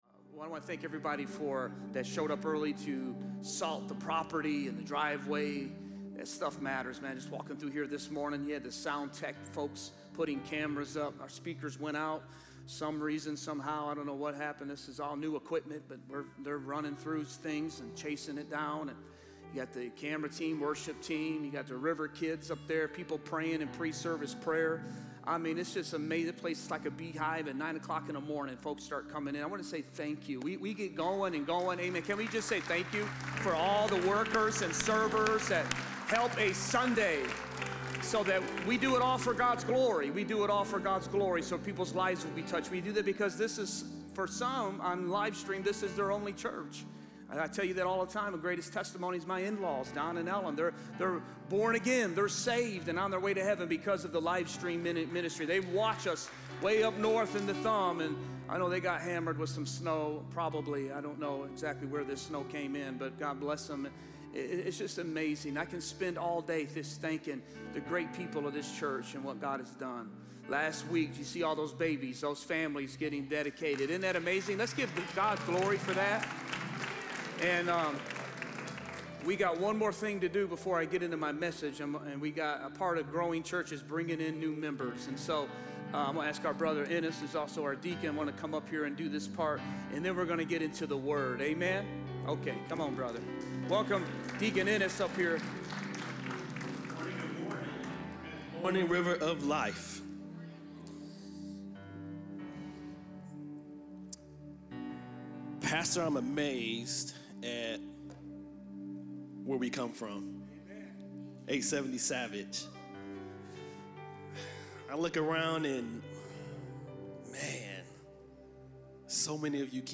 Sermons | River of Life Church